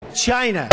Download Trump Say China sound effect for free.
Trump Say China